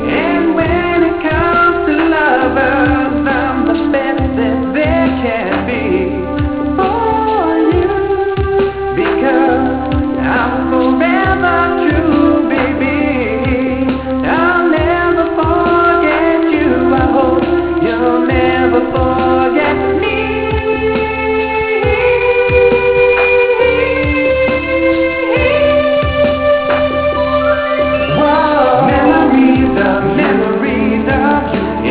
STYLE: R&B Ballad